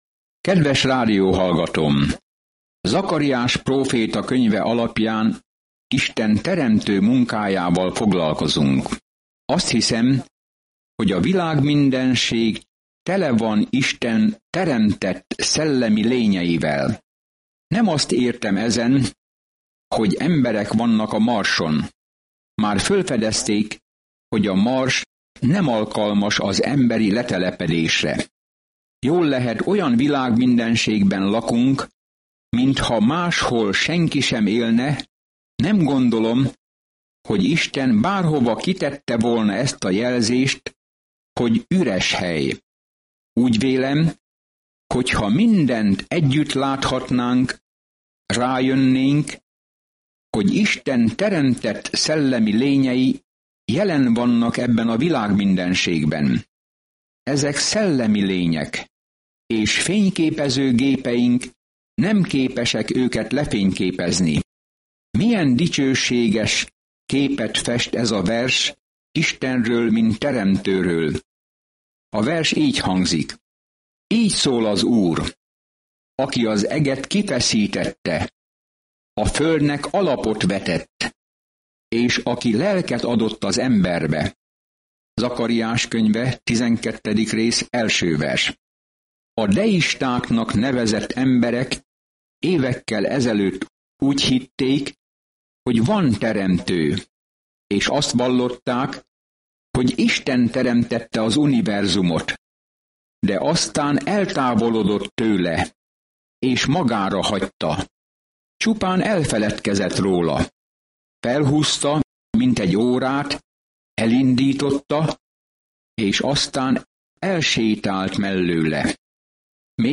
Szentírás Zakariás 12:2-9 Nap 25 Terv elkezdése Nap 27 A tervről Zakariás próféta látomásokat oszt meg Isten ígéreteiről, hogy reményt adjon az embereknek a jövőre nézve, és arra buzdítja őket, hogy térjenek vissza Istenhez. Napi utazás Zakariáson keresztül, miközben hallgatod a hangos tanulmányt, és olvasol válogatott verseket Isten szavából.